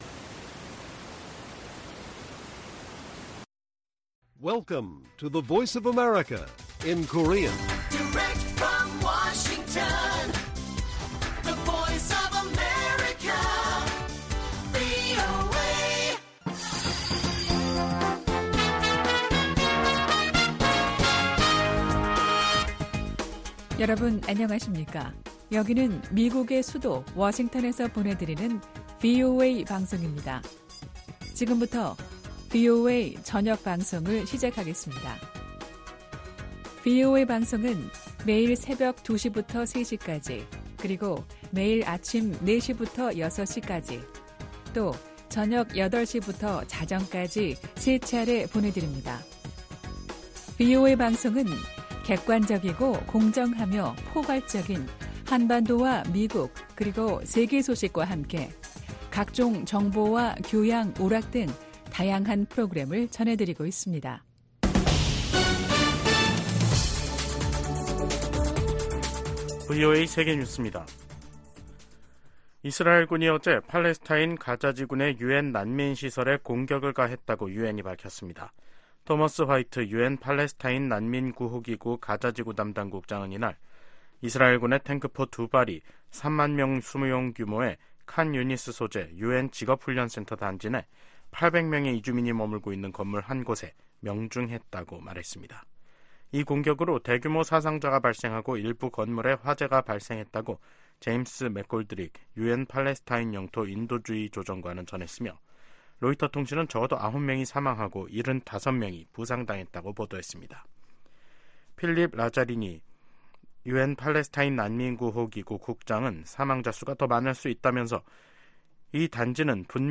VOA 한국어 간판 뉴스 프로그램 '뉴스 투데이', 2024년 1월 25일 1부 방송입니다. 북한이 신형 전략순항미사일을 첫 시험발사했다고 밝혔습니다.